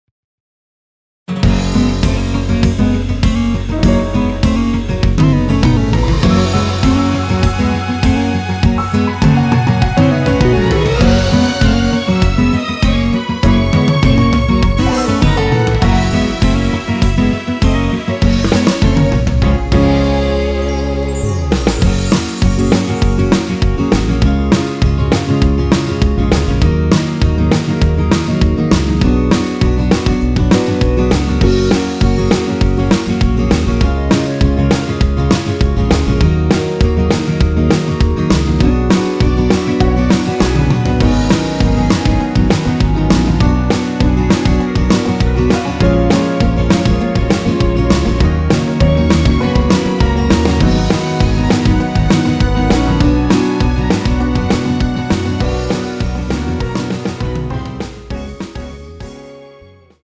원키 멜로디 포함된 MR입니다.(미리듣기 확인)
Gb
앞부분30초, 뒷부분30초씩 편집해서 올려 드리고 있습니다.
중간에 음이 끈어지고 다시 나오는 이유는